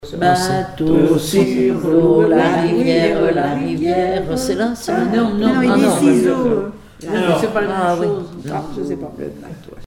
formulette enfantine : sauteuse
comptines et formulettes enfantines
Pièce musicale inédite